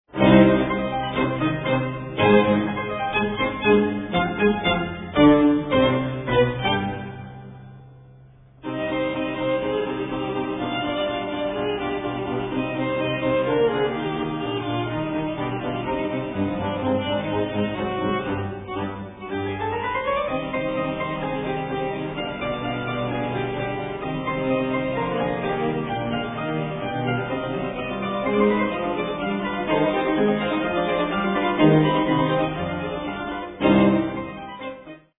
for Violin, Cello and Harpsichord